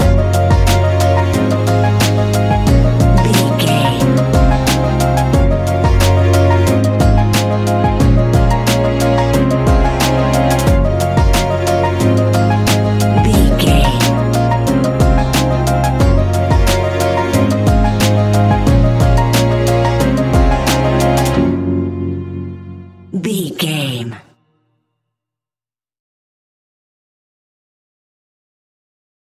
Ionian/Major
C♭
Lounge
sparse
new age
chilled electronica
ambient
atmospheric